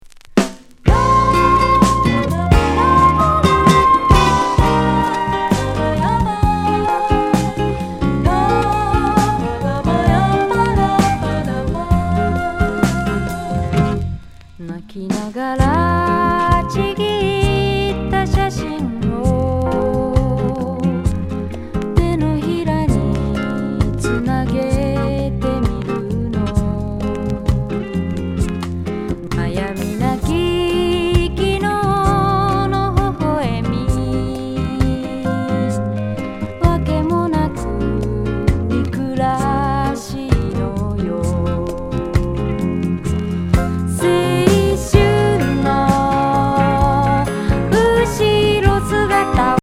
骨抜スムース・アンニュイ・シャバダバ・メロウSSW♥